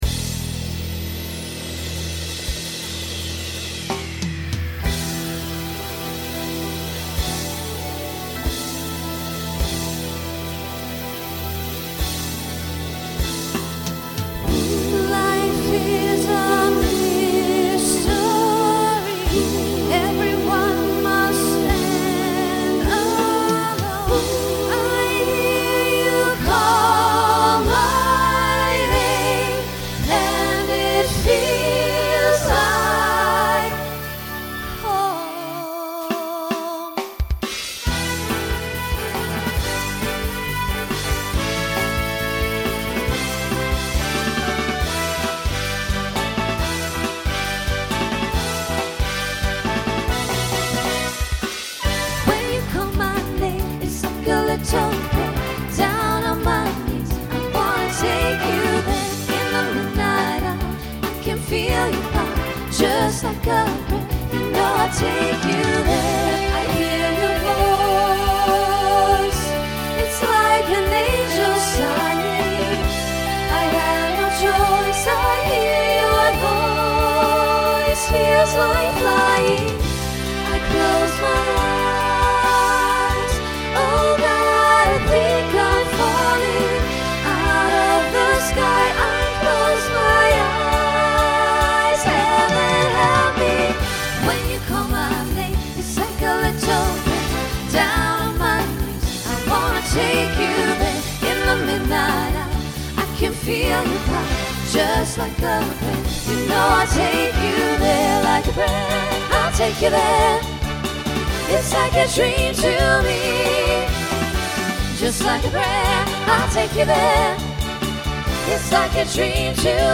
SSA Instrumental combo
Pop/Dance